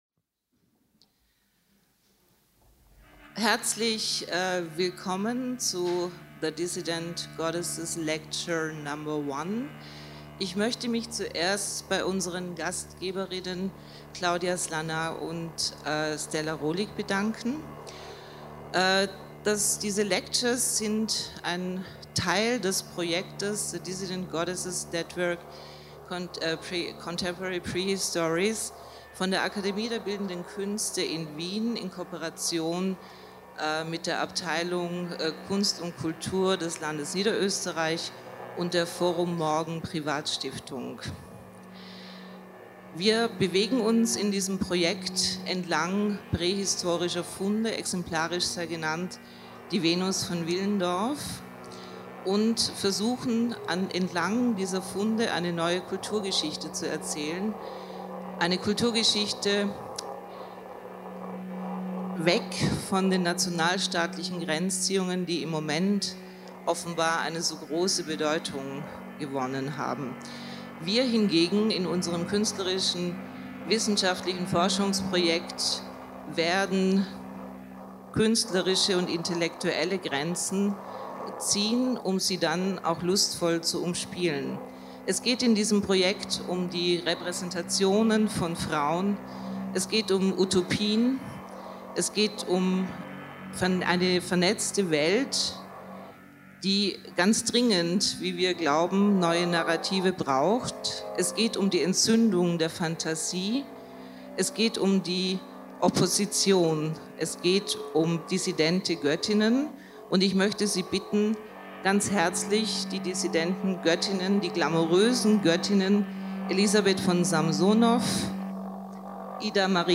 Lecture #1 | THE DISSIDENT GODDESSES´ NETWORK
The Dissident Goddesses‘ Lecture #1 Blickle Kino im Belvedere 21, Arsenalstrasse 1, 1030 Wien Freitag, 12. April 2019, 17 Uhr